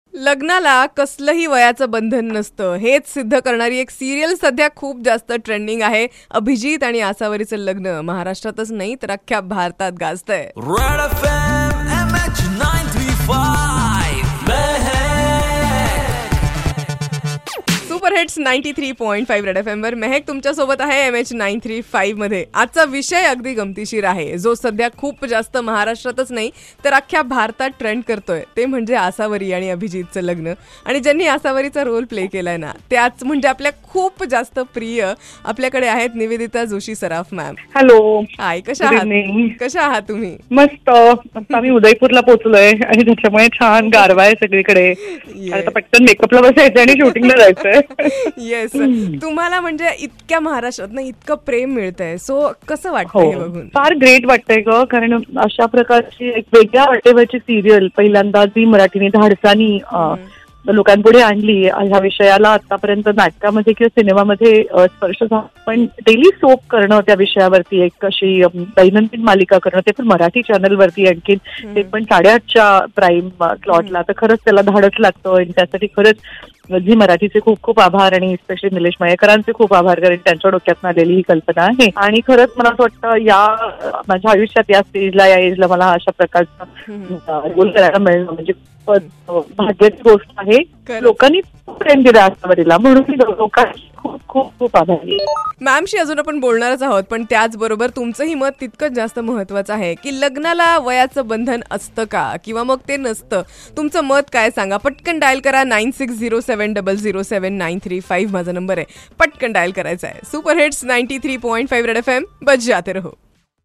Lagnala vay asta ka: Nivedita Saraf Interview